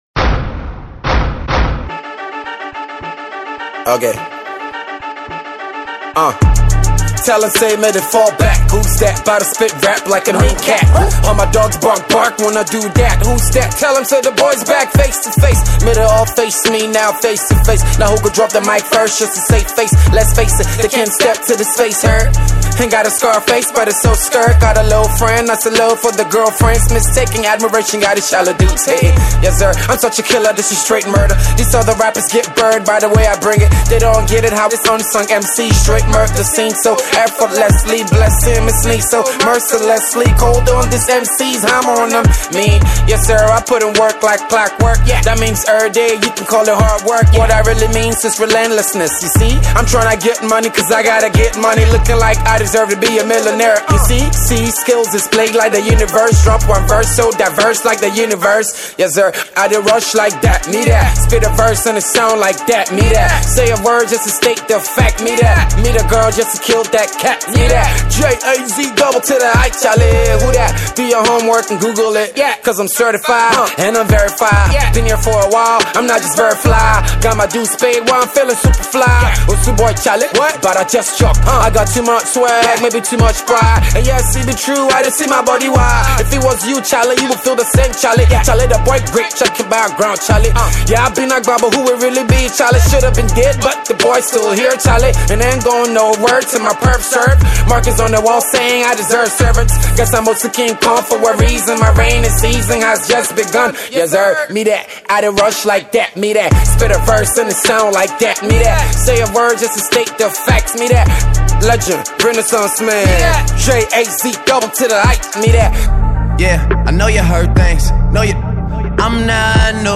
Ghanaian MC